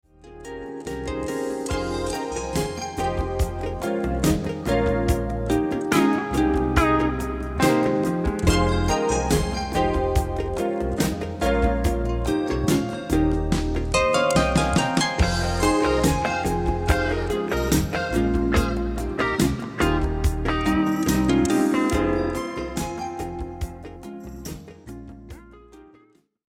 electracoustic pedal harp, gu-cheng & more...
Recorded and mixed at the Sinus Studios, Bern, Switzerland